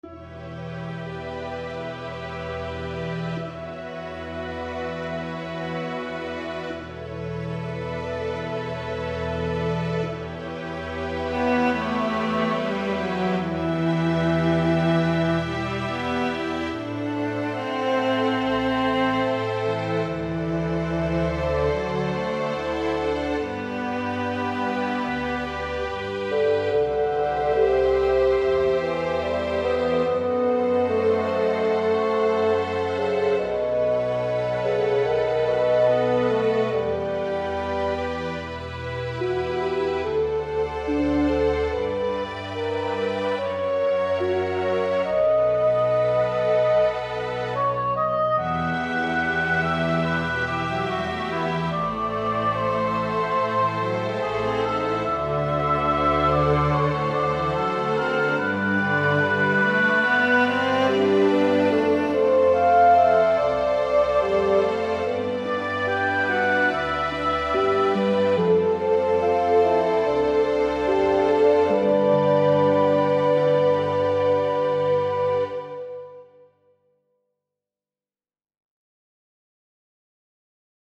3RD VERSION: With French Horn instead of Solo Trumpet .
This is the 3rd version, but with French Horn instead of Solo Trumpet, and for reference I left the panning at full-range, since as explained in my previous reply, the instruments in Miroslav Philharmonik are recorded in their standard locations onstage in a symphonic hall, although in some instances there also are studio recordings where instruments are recorded being played in isolation booths and so forth . . .